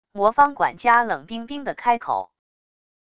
魔方管家冷冰冰的开口wav音频